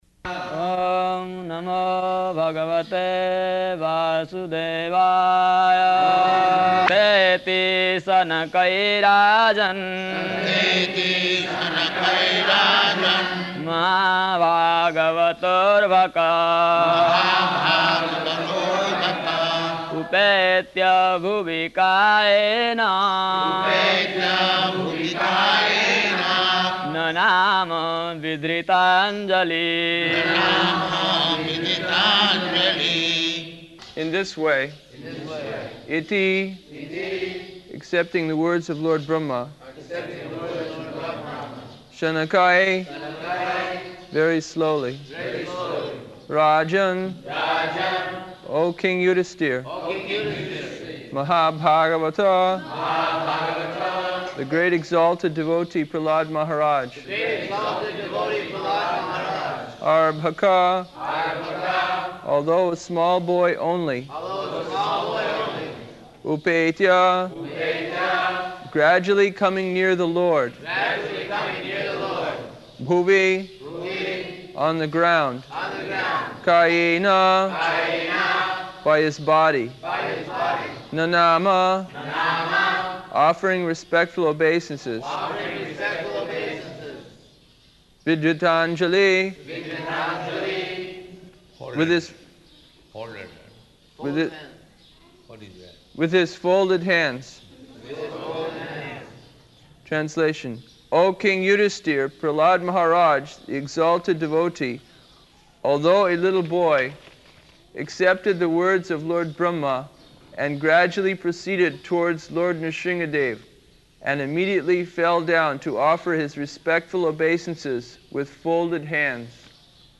-- Type: Srimad-Bhagavatam Dated: February 11th 1976 Location: Māyāpur Audio file